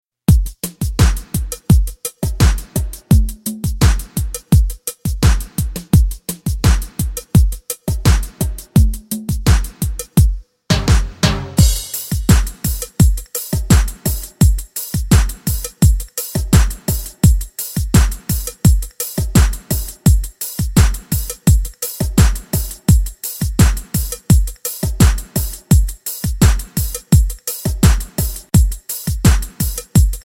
MP3 Demo Instrumental Version